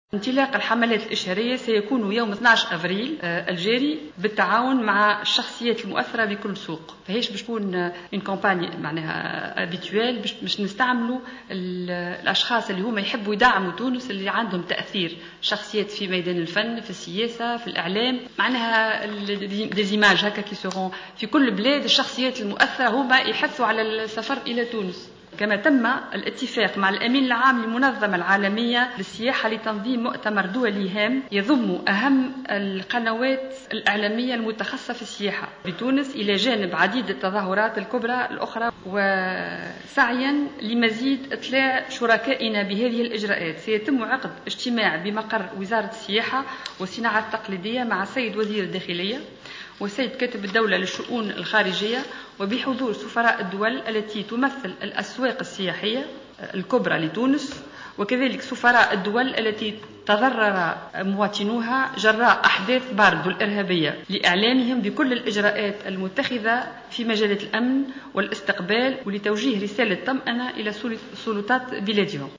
Le ministre du tourisme Salma Elloumi a présenté lors de la conférence de presse organisée ce vendredi 10 avril 2015, les 5 priorités de son ministère.